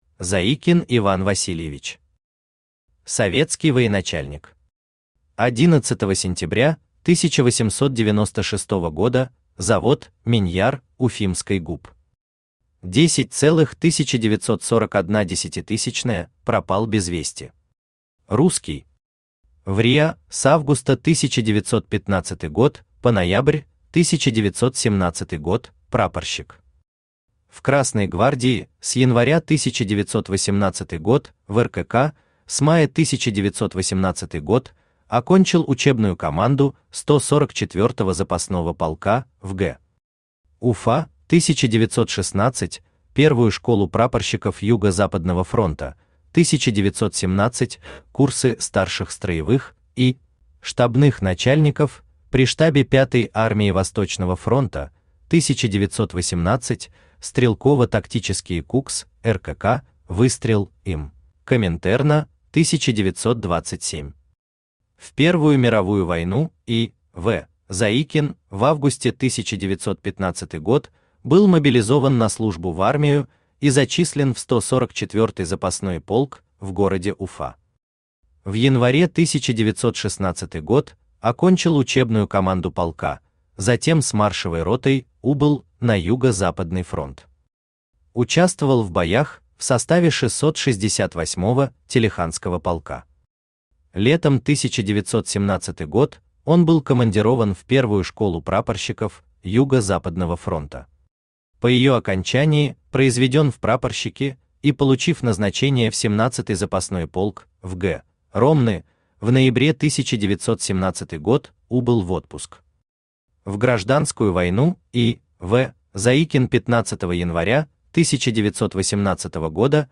Aудиокнига Командиры дивизий Красной Армии 1921-1941 гг. Том 12 Автор Денис Соловьев Читает аудиокнигу Авточтец ЛитРес.